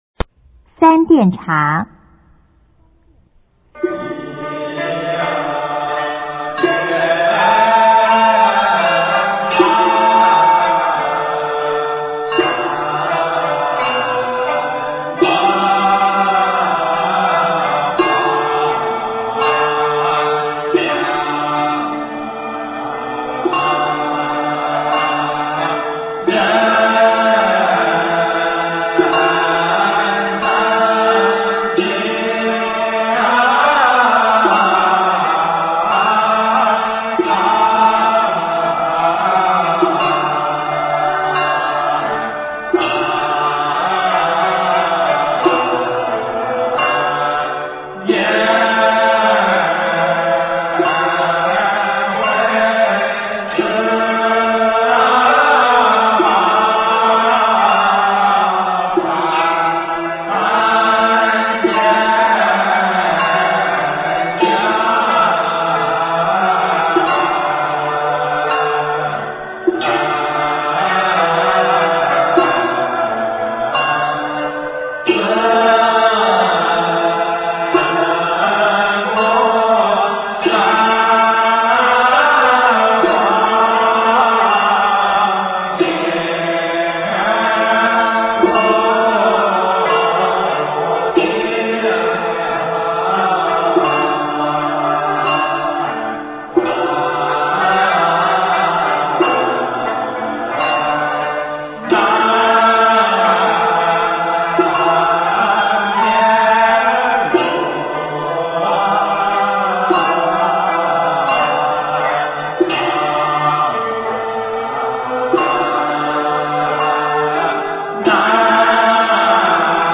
中国道教音乐 全真正韵 三奠茶